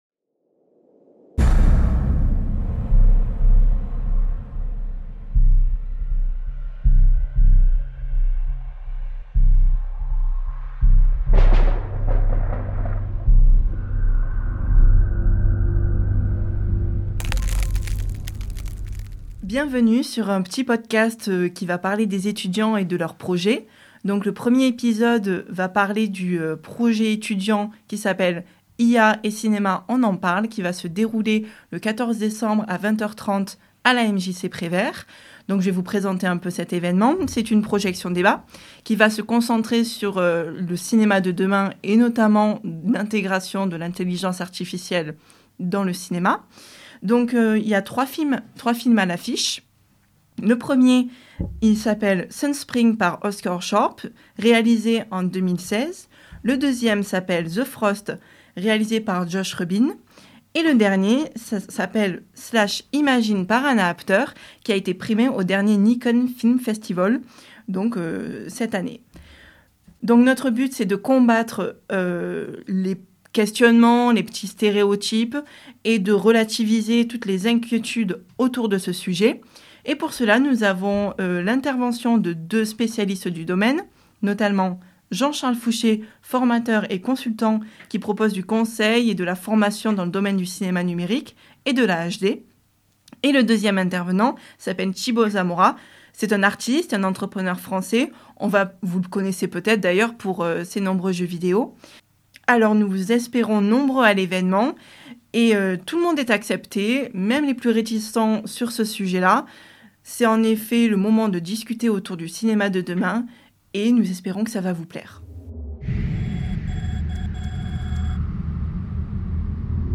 intro music : The Frost de Josh Rubin